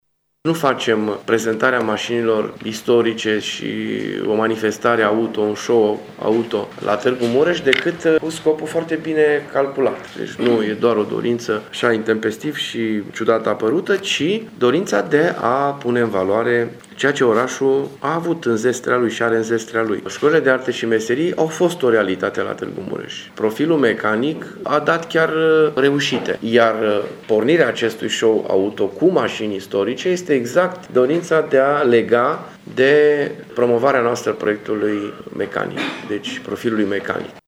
Și etapa de raliu a mașinilor de epocă ce va avea loc între 4 și 7 iunie a fost gândită tot în acest context, a explicat primarul Dorin Florea: